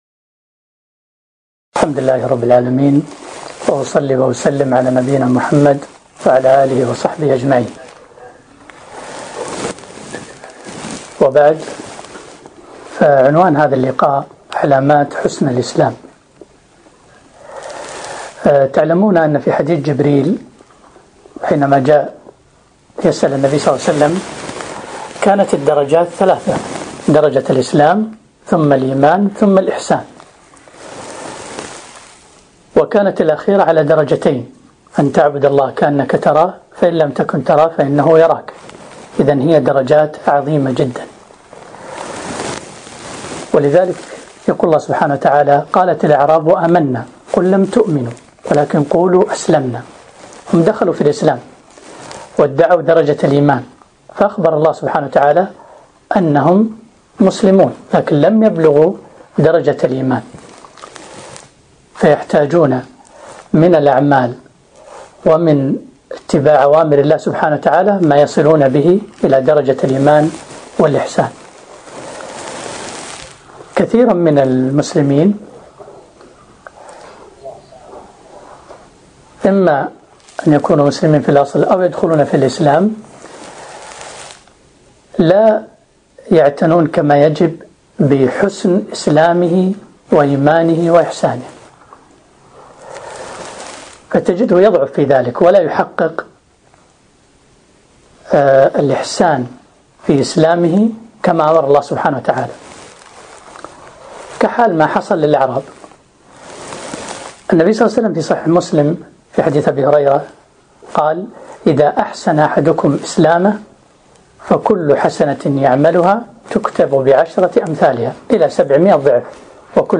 محاضرة - علامات حسن الإسلام